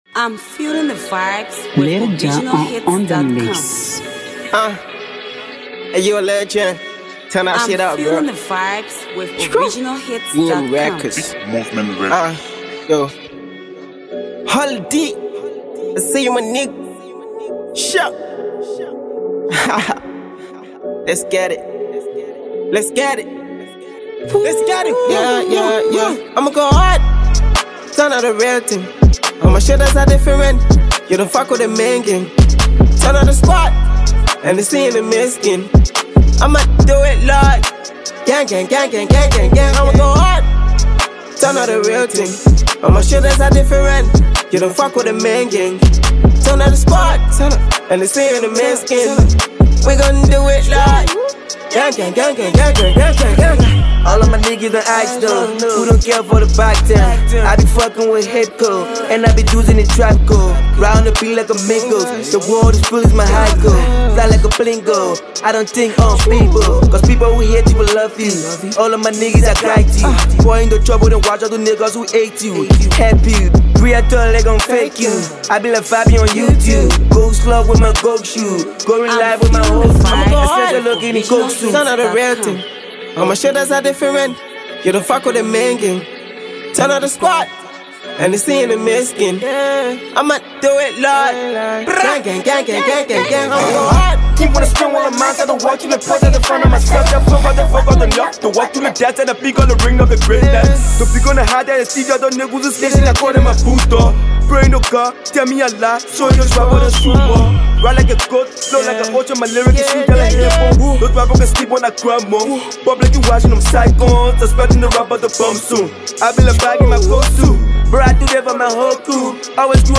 Multi-talented uprising trap artist